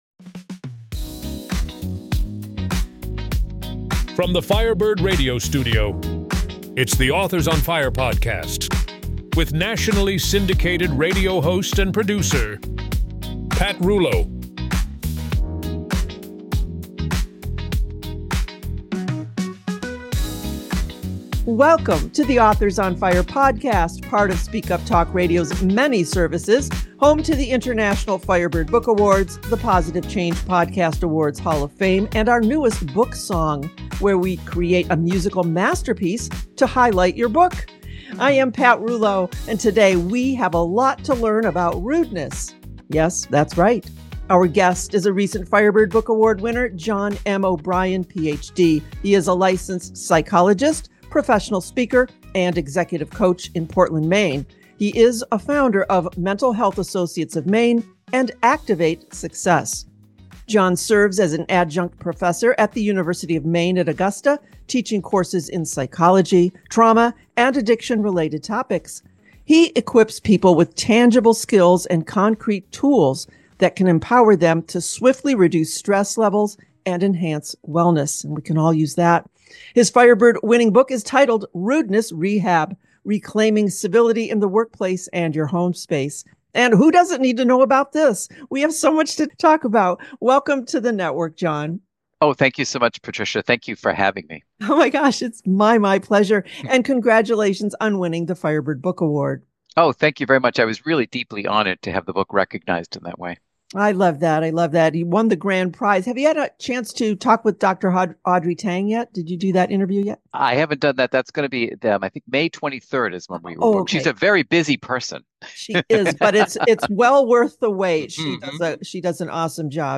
Firebird Book Award Winner Author Interview